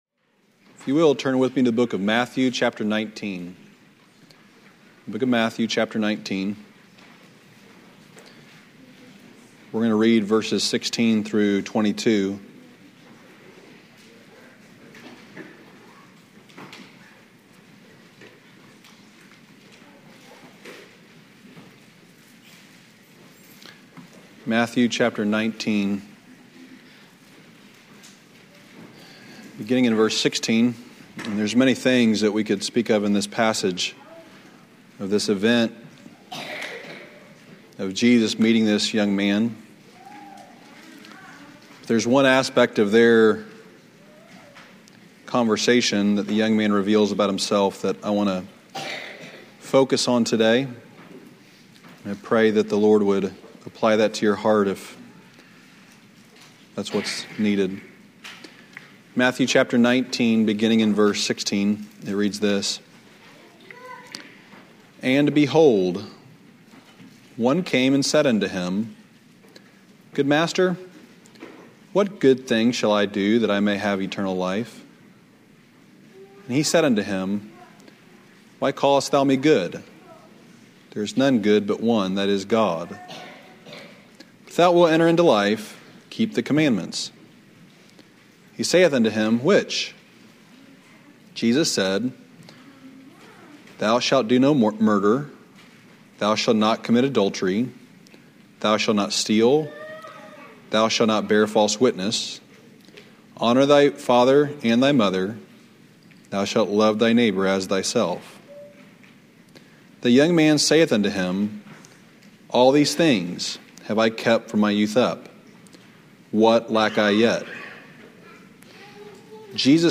Lesson 1 from the 2007 Old Union Ministers School.